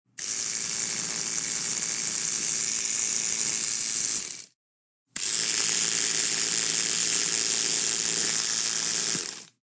Download Electronic sound effect for free.
Electronic